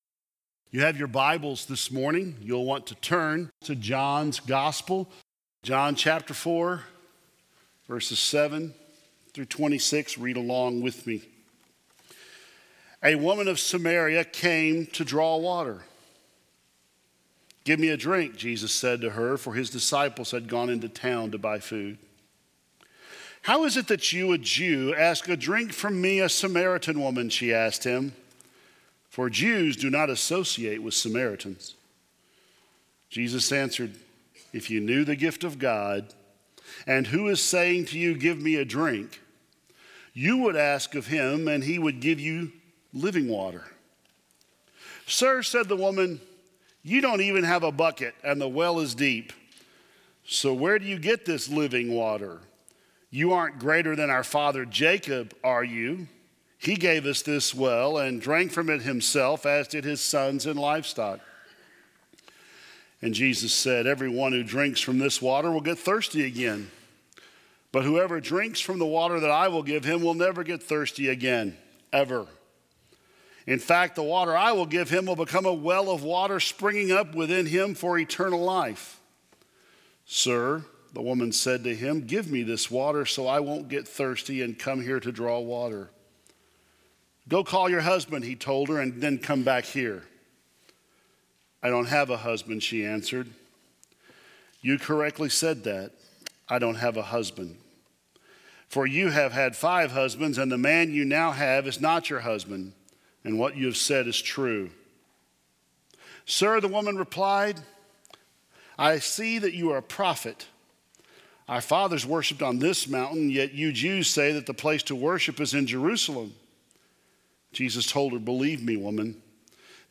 2017 Related Share this sermon